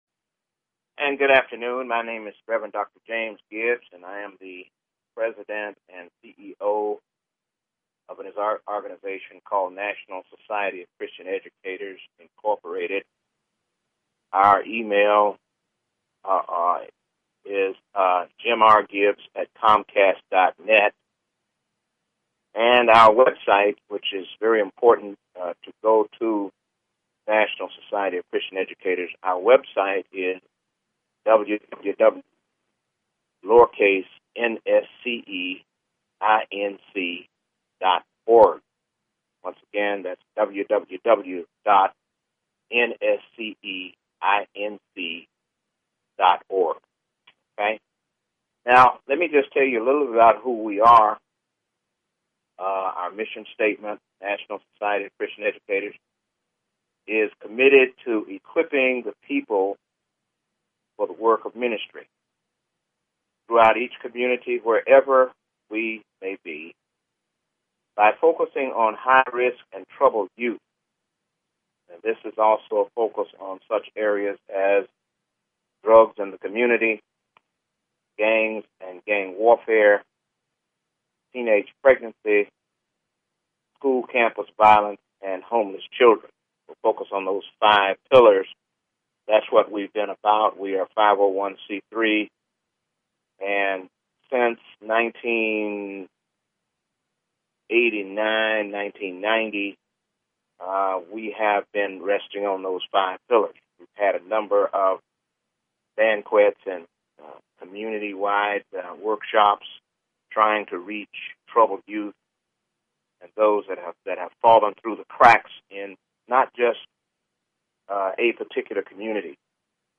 Talk Show Episode, Audio Podcast, Solutions and Courtesy of BBS Radio on , show guests , about , categorized as